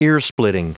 Prononciation du mot earsplitting en anglais (fichier audio)
Prononciation du mot : earsplitting